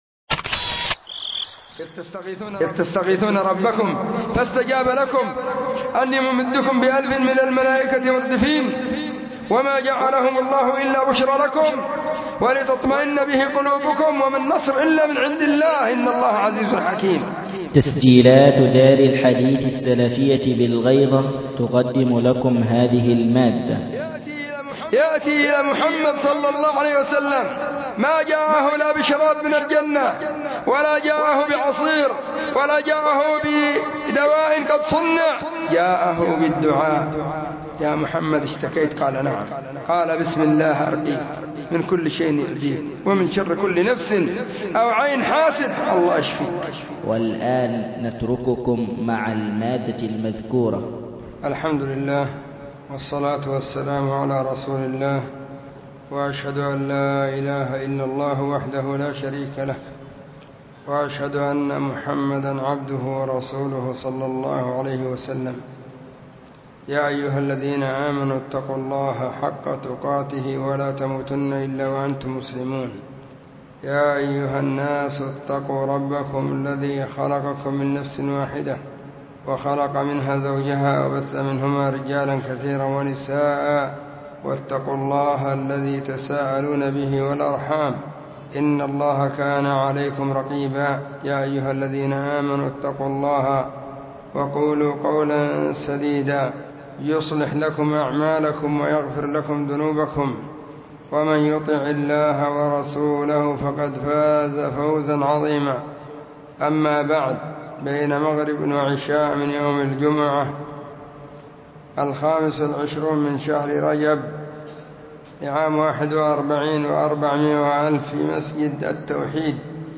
محاضرة بعنوان: بعض أسباب الفرج من البلاء.
🗓 الجمعة 25/رجب/ 1441 هجرية الحمد لله والصلاة والسلام على رسول الله أما بعد: 🎙 فهذه محاضرة – في مسجد التوحيد بمنطقة قشن المهرة.